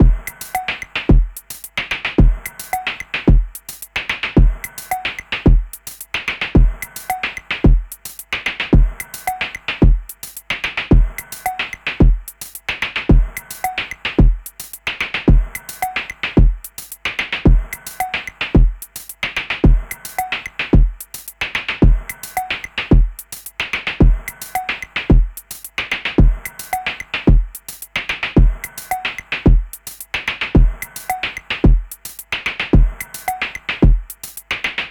Bucle de percusión electrónica
Música electrónica
melodía
repetitivo
sintetizador